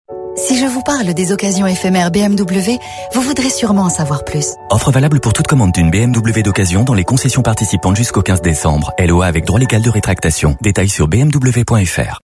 Sa diction irréprochable, sa rigueur technique et sa capacité à garder une intention malgré la contrainte de temps font toute la différence.
1. BMW mention légale 0:12